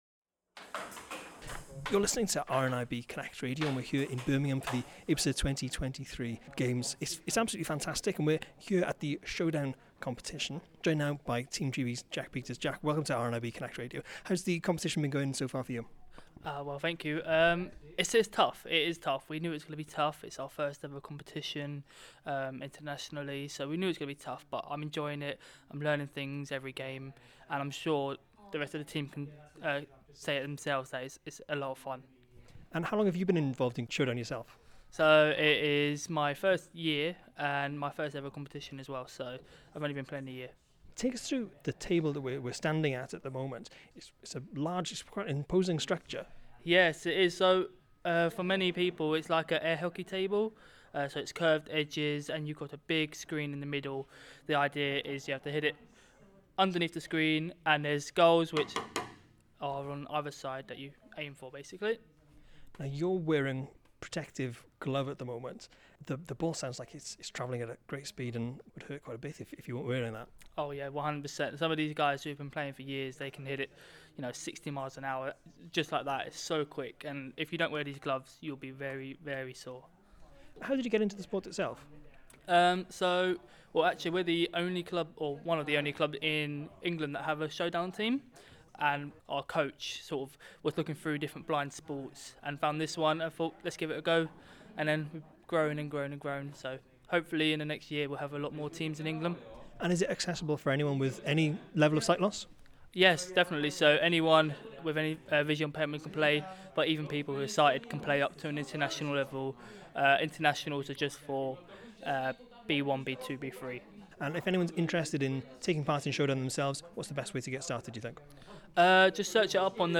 between matches at the IBSA World Games